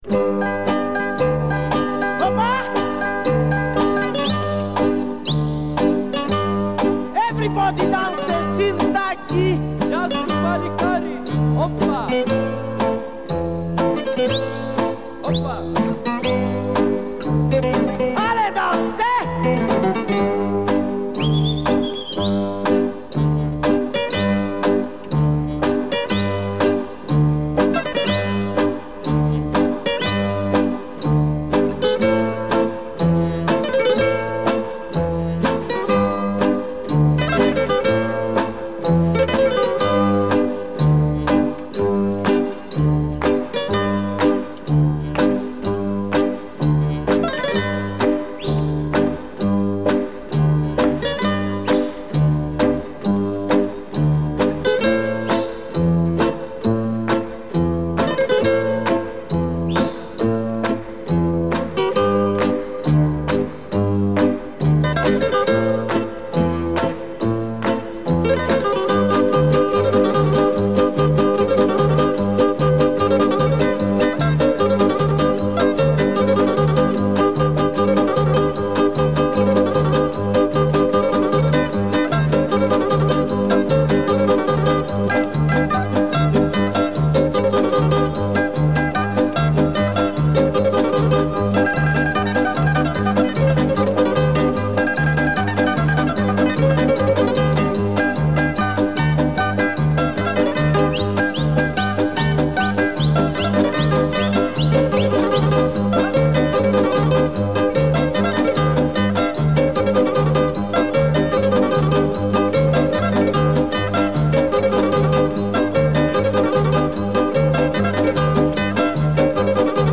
Музыкальное трио из солнечной Греции.
Музыкальный стиль Laika.
Genre: Folk, Instrumental